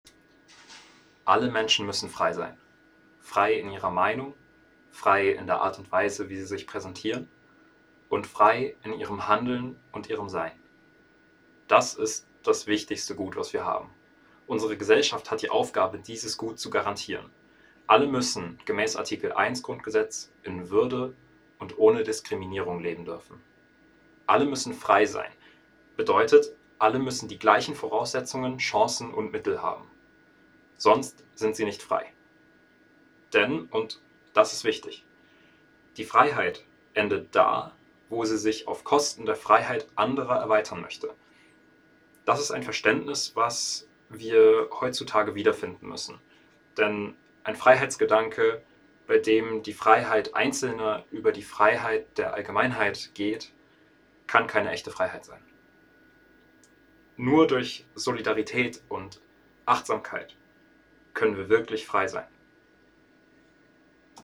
Ein Fest für die Demokratie @ Bundeskanzleramt, Berlin